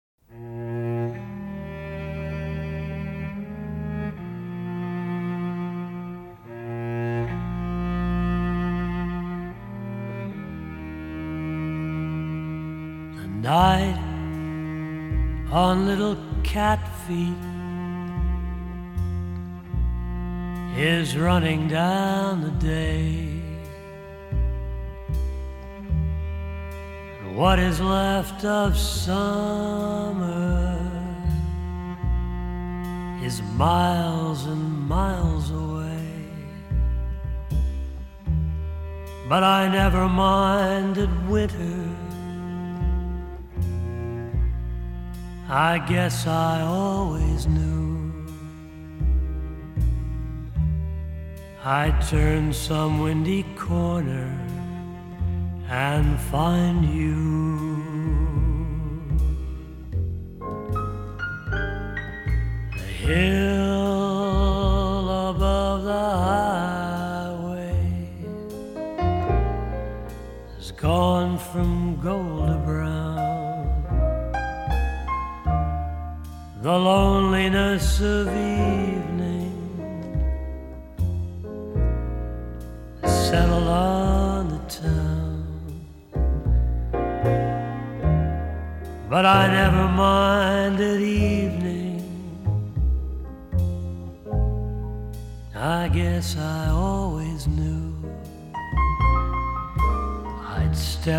★柔和而富磁性的歌聲，輕緩悠揚的爵士風情，為您在夜間點上一盞綻放溫暖光芒的燈。
低沉富磁性的嗓音更增添了歲月的風采，傳統爵士的鋼琴、吉他、薩克斯風外，更以大提琴、笛子等增添浪漫悠揚的感性